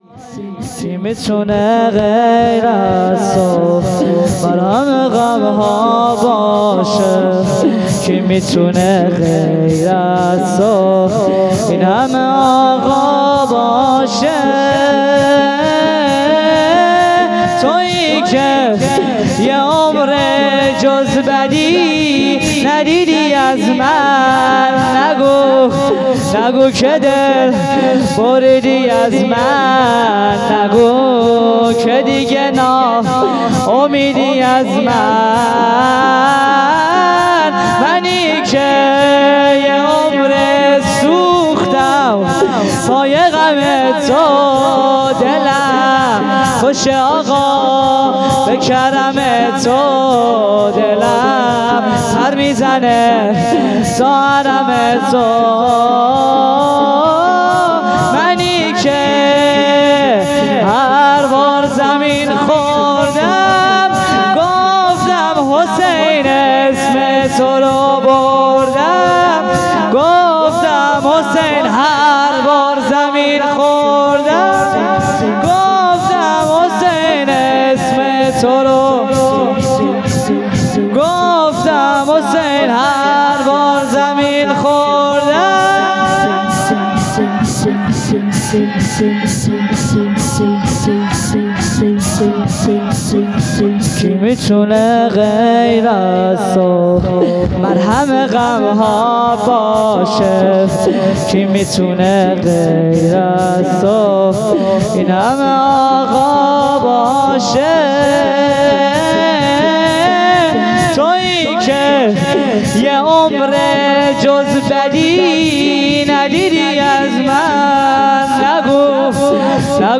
بخش چهارم _ شور | کی میتونه غیر از تو